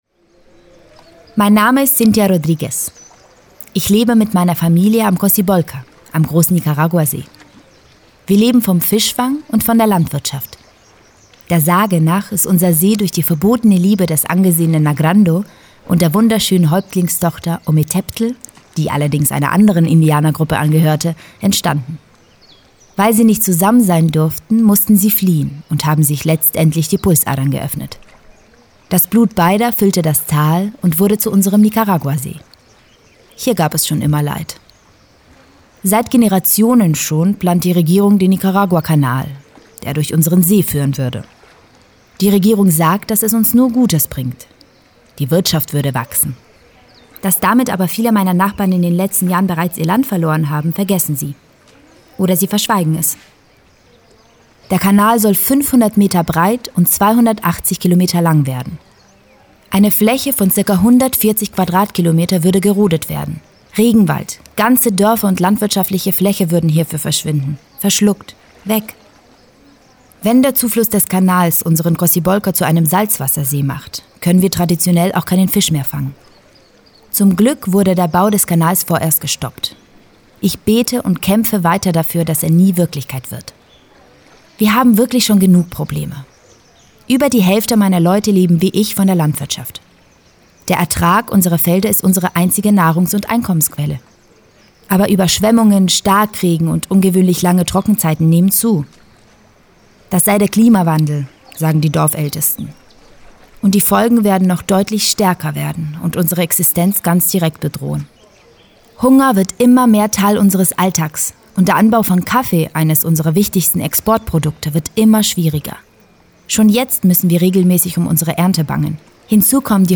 Eine Fischerin erzählt: